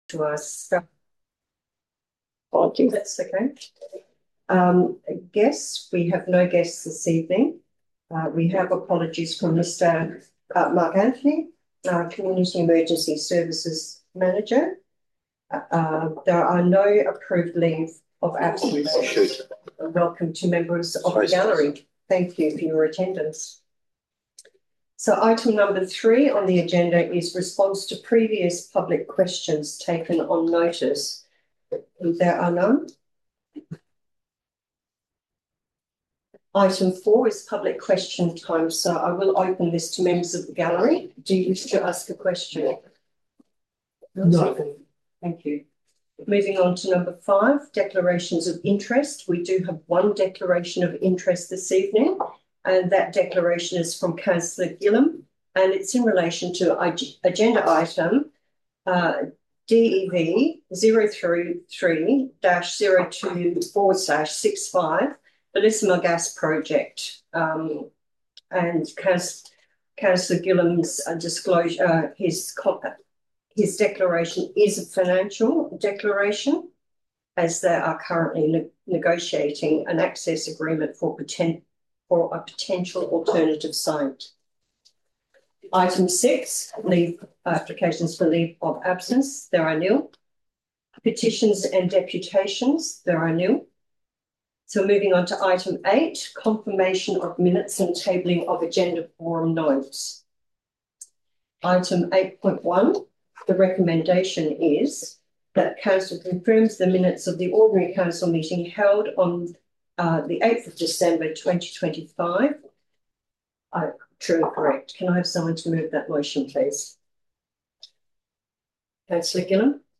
24 February 2026 Ordinary Council Meeting - Shire of Irwin